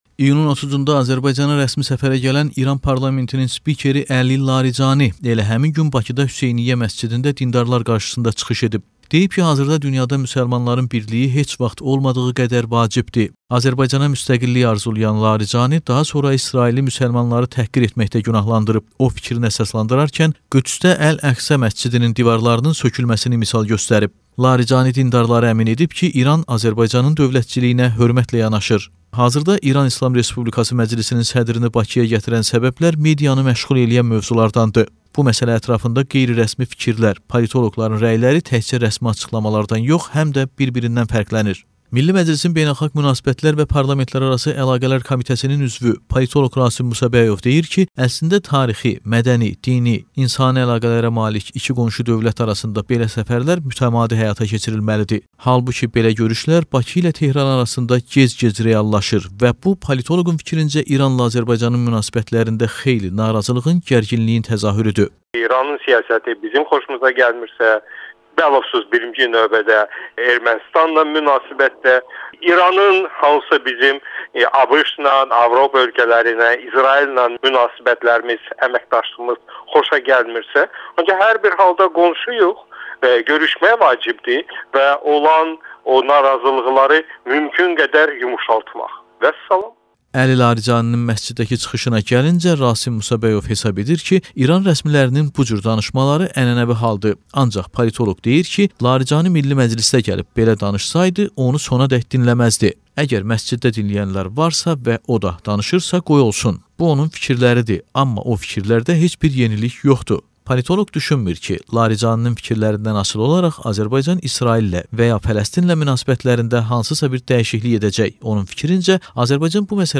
İyunun 30-da Azərbaycana rəsmi səfərə gələn İran parlamentinin spikeri Əli Laricani Bakıda Hüseyniyə məscidində dindarlar qarşısında çıxış edib